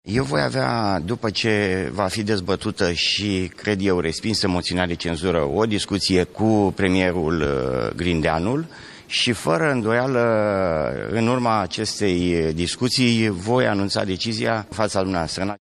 Ministrul Justiţiei le-a declarat jurnaliştilor că va avea o discuţie cu premierul despre viitorul său în echipa guvernamentală.
ASCULTA MAI JOS DECLARATIILE MINISTRULUI JUSTIȚIEI
06feb-09-Iordache-voi-avea-o-discutie-cu-premierul.mp3